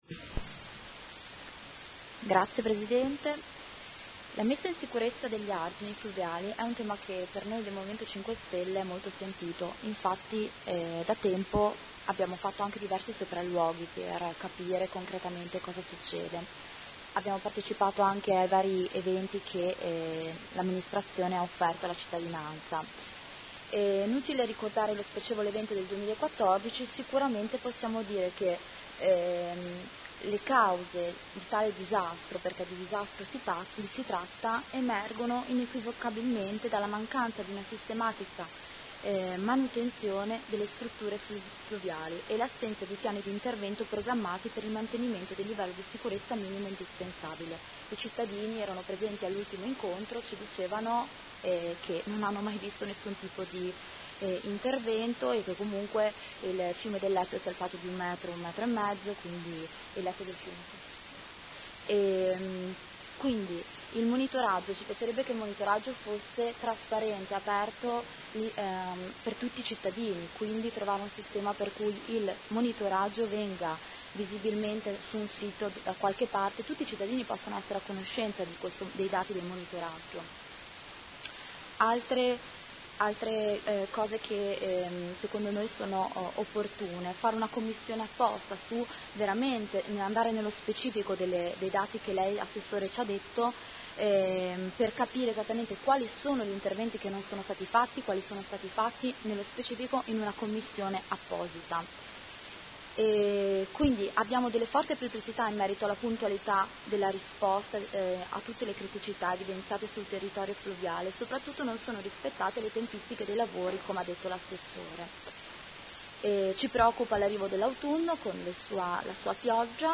Seduta del 22 ottobre. Interrogazione del Consigliere Carpentieri (P.D.) avente per oggetto: Interventi AIPO per la messa in sicurezza degli argini fluviali. Discussione